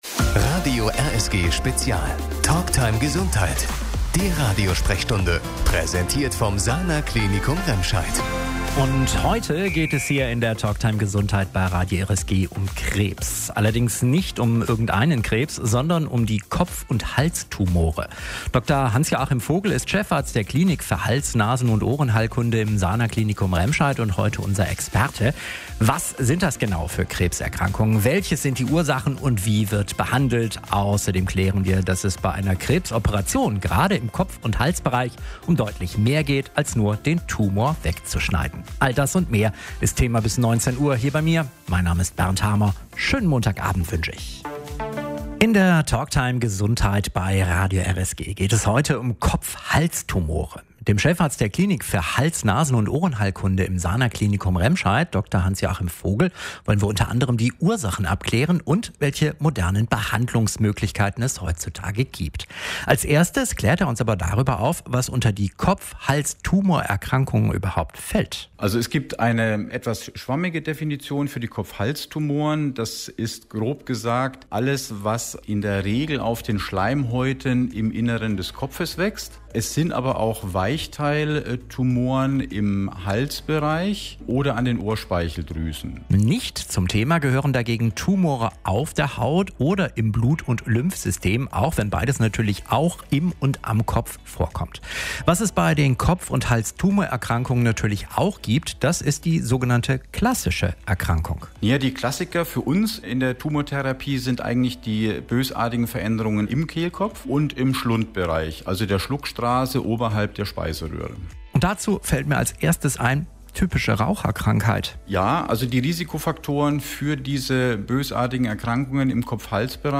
Das Interview